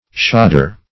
Search Result for " shoder" : The Collaborative International Dictionary of English v.0.48: Shoder \Sho"der\, n. A package of gold beater's skins in which gold is subjected to the second process of beating.